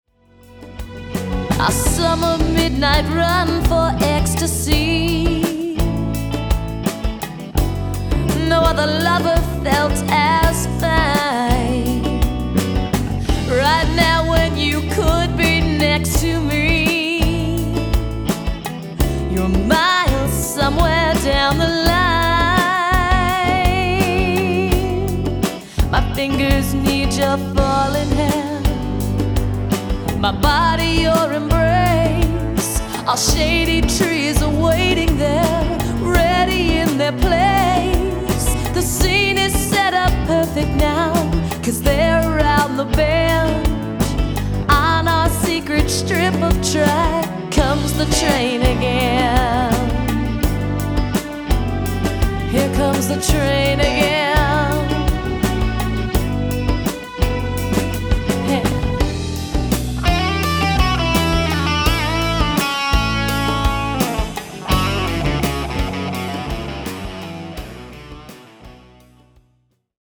Vocals
Guitar, bass, percussion, string programming, and drums